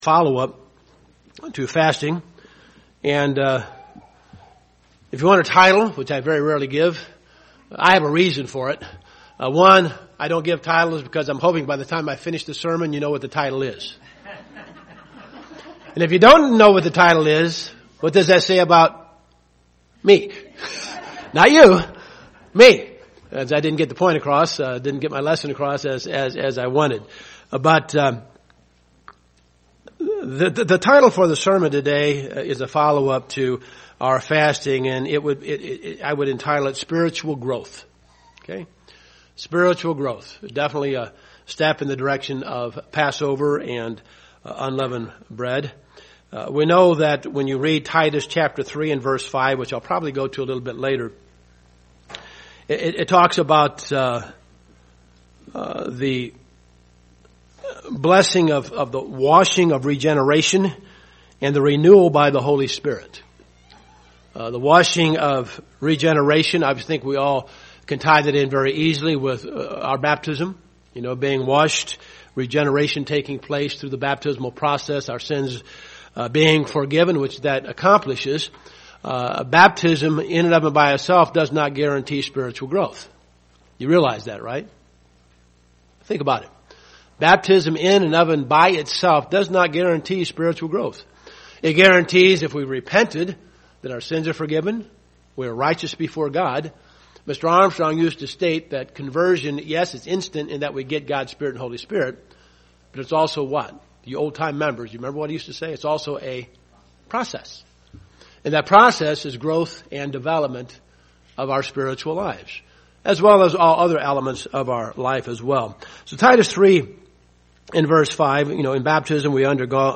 Spiritul growth is a choice that is blessed by God UCG Sermon Studying the bible?